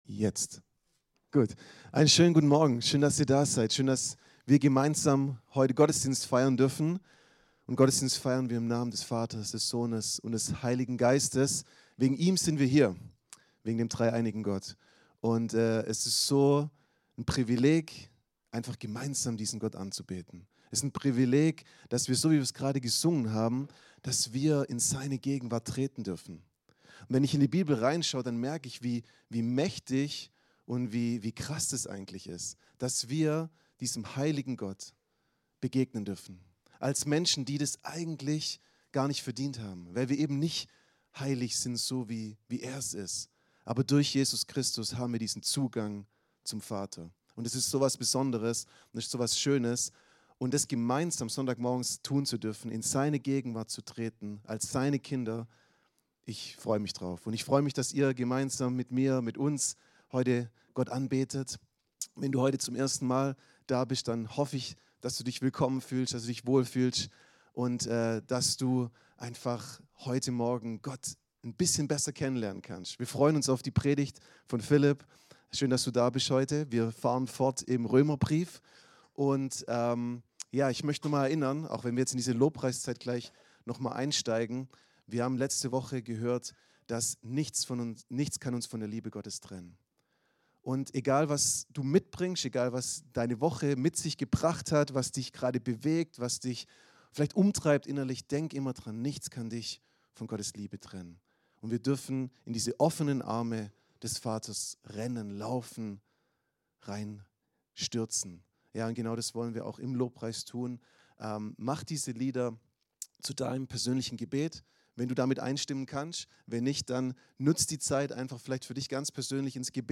Diese Predigt hilft dir zu verstehen: Ein erneuertes Denken ist der Schlüssel, um Gottes Willen zu erkennen und ein Leben zu führen, das wirklich gut, heilsam und erfüllend ist.